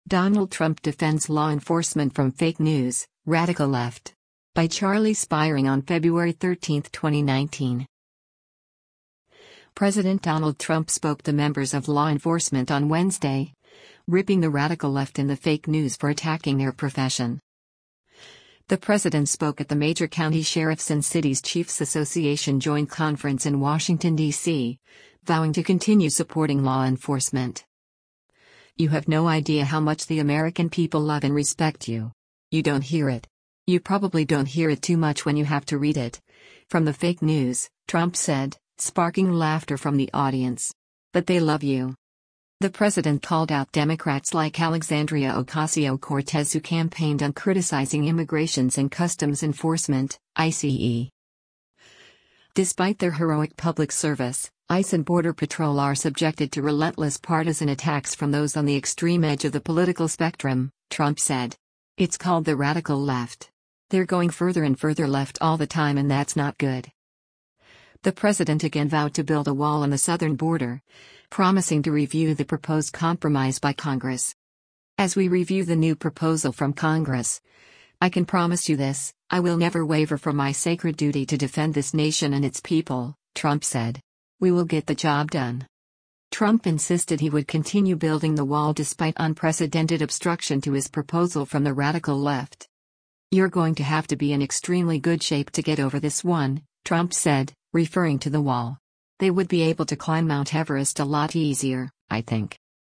The president spoke at the Major County Sheriffs and Cities Chiefs Association Joint Conference in Washington, DC, vowing to continue supporting law enforcement.
“You have no idea how much the American people love and respect you. You don’t hear it. You probably don’t hear it too much when you have to read it, from the fake news,” Trump said, sparking laughter from the audience.